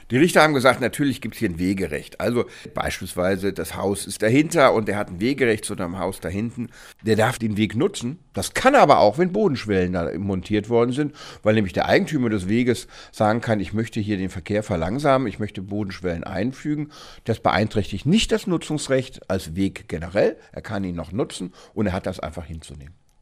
O-Ton: Gericht erlaubt Bodenschwellen auf Privatweg – Vorabs Medienproduktion